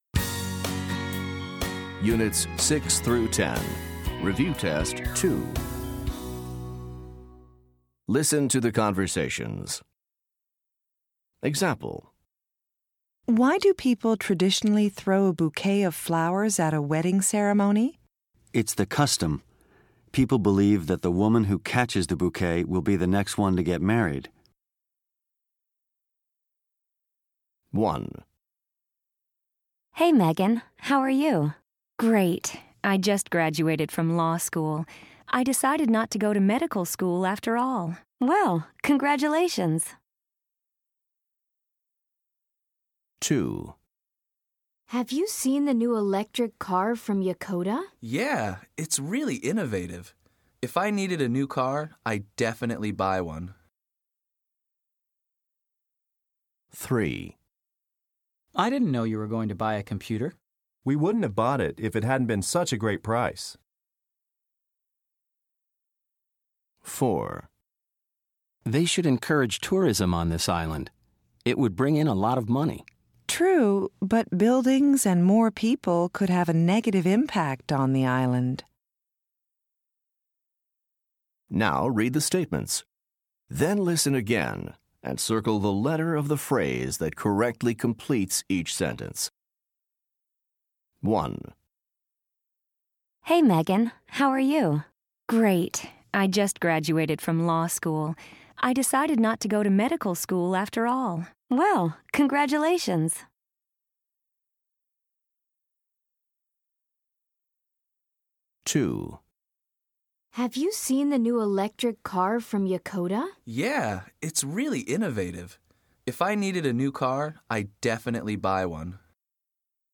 Listen to the conversations. Choose the phrase that correctly completes each sentence.